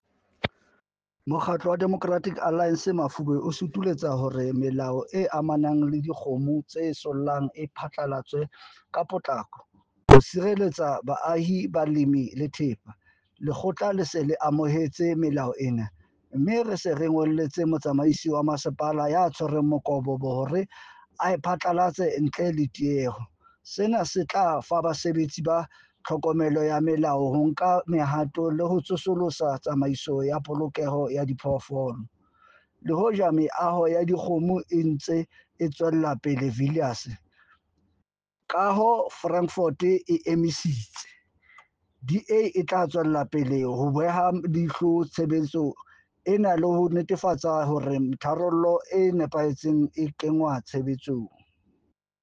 Sesotho soundbite by Cllr Fako Tsotetsi.